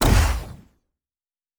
Sci Fi Explosion 17.wav